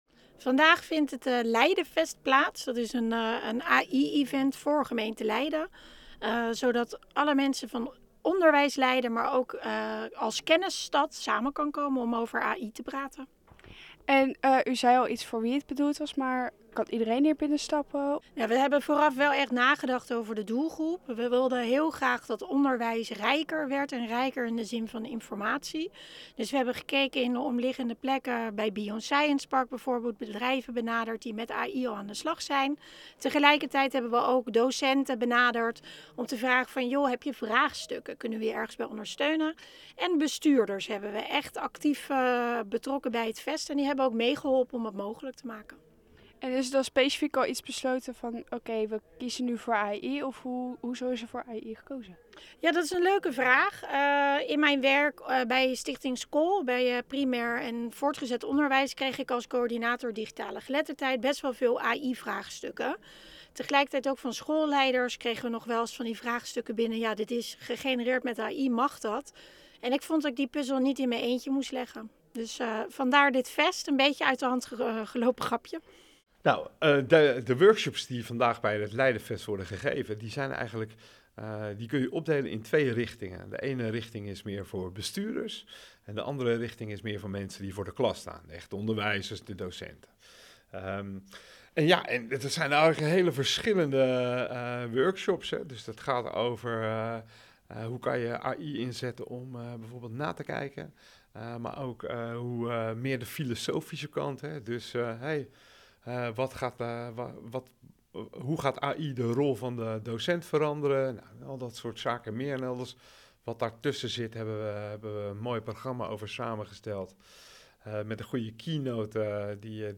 Verslaggever
in gesprek